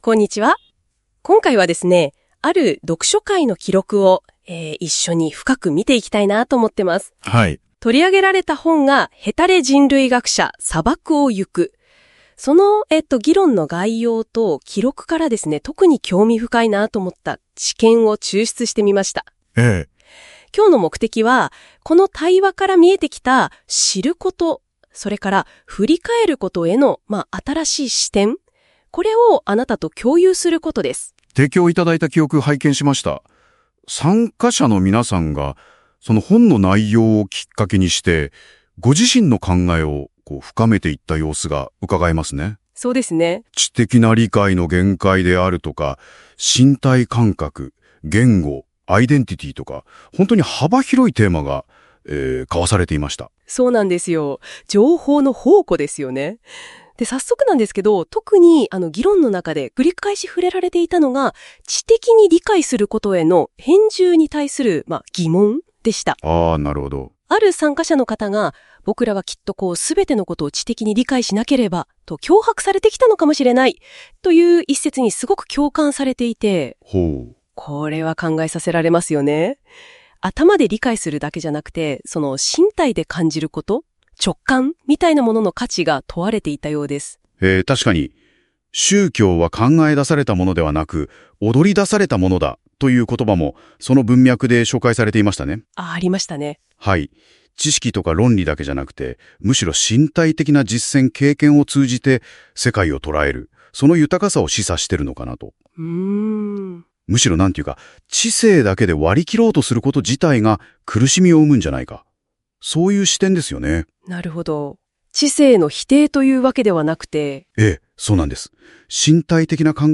「ヘタレ人類学者、砂漠をゆく」読書会 第4回の会議メモから生成したふりかえり用Podcastです